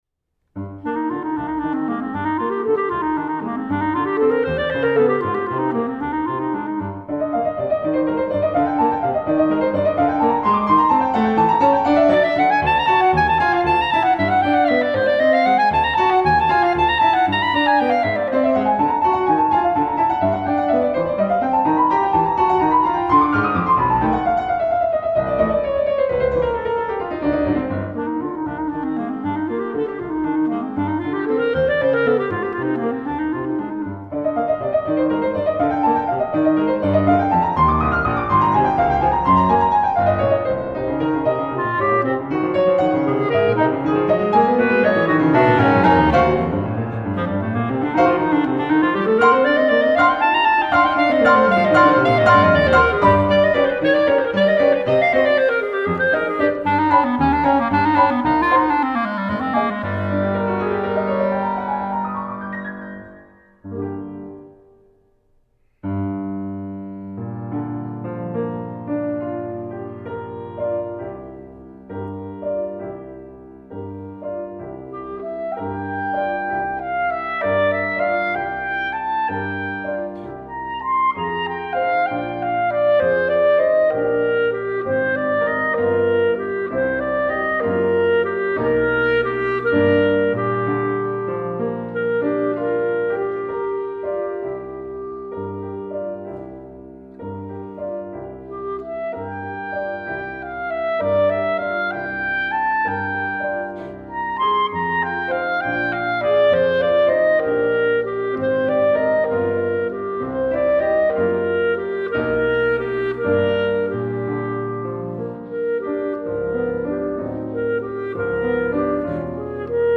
2. Fast